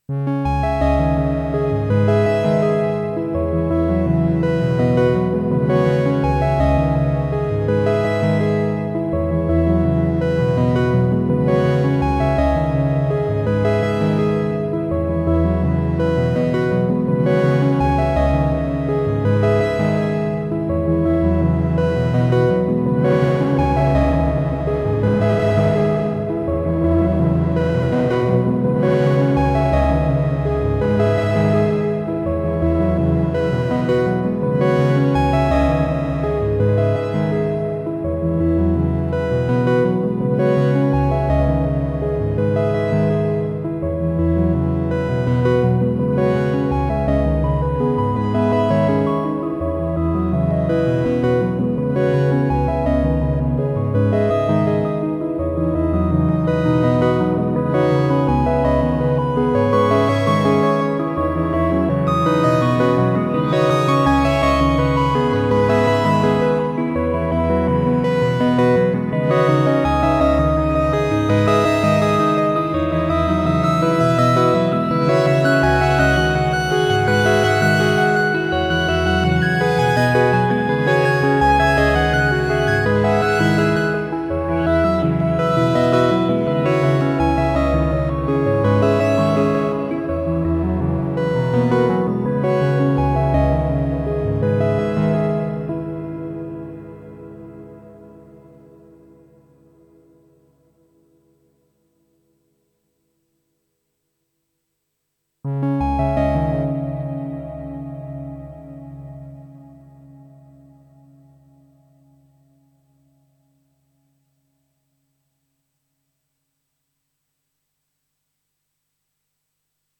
Modal Argon8 – 8 voice polyphonic wavetable synthesiser
I think it sounds really really nice.
Edit 2: Turns out this is an arp, and patch #1 on the synth.